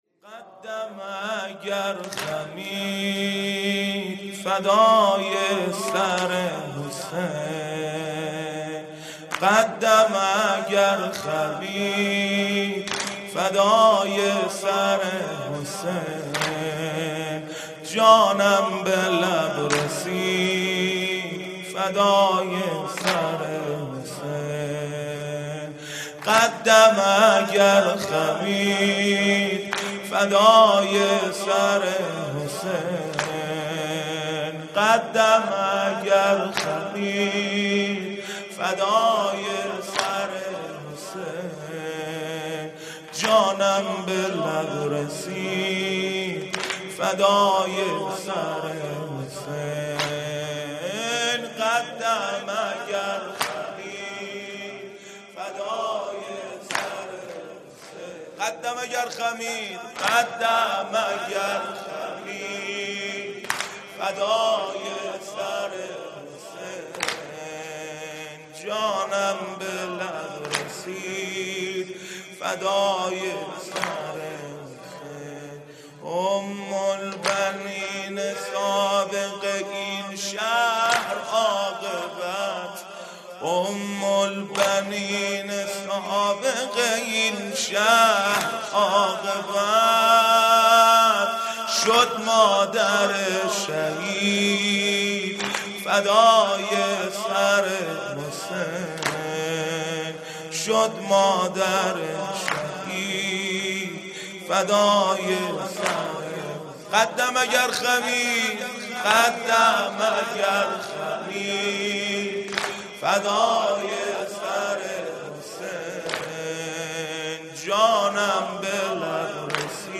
جلسه هفتگی | ۱۷ اسفند ۱۳۹۵